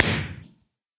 blast1.ogg